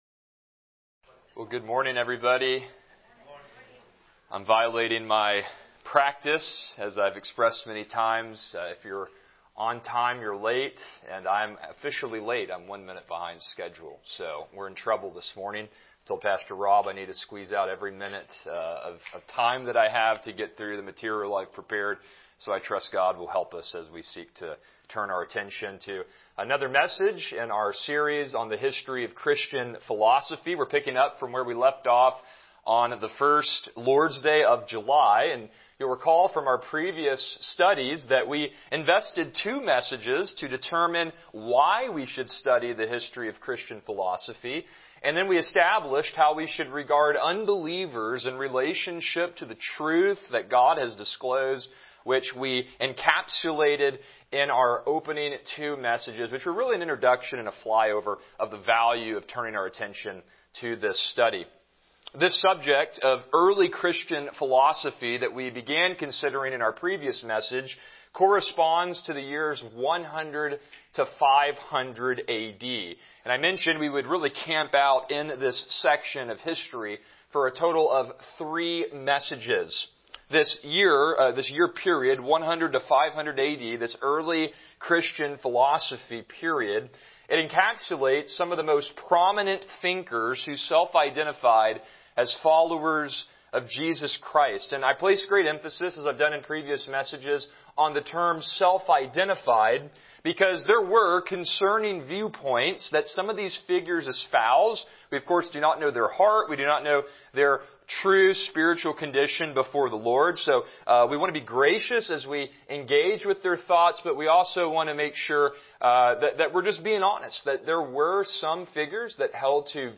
Series: Christianity and Philosophy Service Type: Sunday School